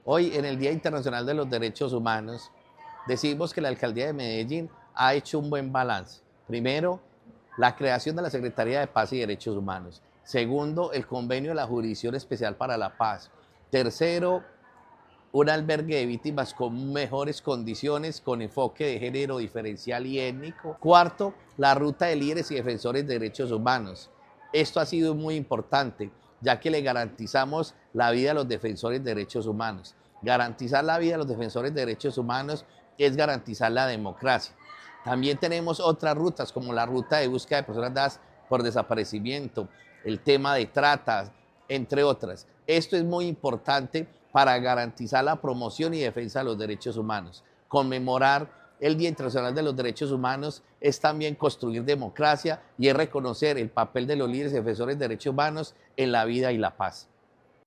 Palabras de Carlos Arcila, secretario de Paz y Derechos Humanos Durante 2024, el Distrito ha efectuado labores psicosociales y jurídicas para acompañar a los ciudadanos, lo que reafirma su compromiso con la defensa de los derechos fundamentales.